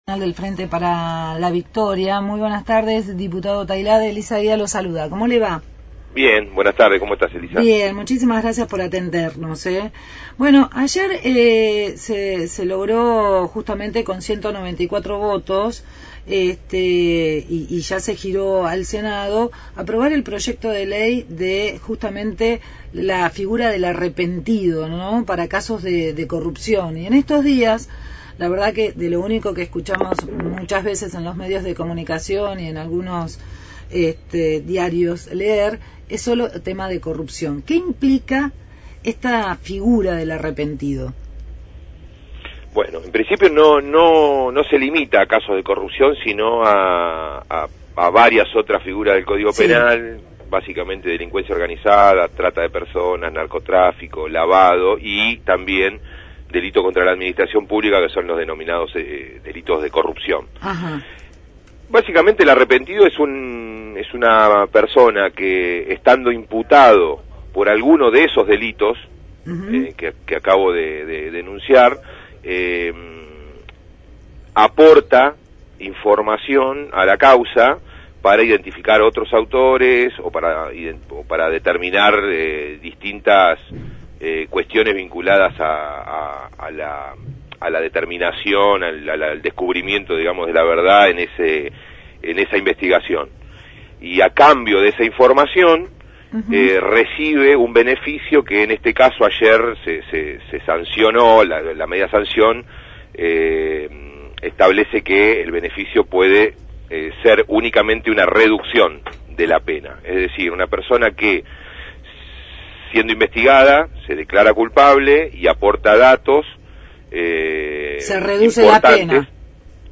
Rodolfo Tailhade, diputado nacional del Frente para la Victoria sobre la Ley del Arrepentido. Programa: Darás que Hablar